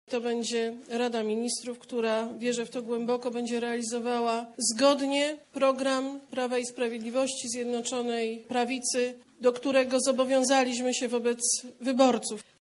– mówi Beata Szydło.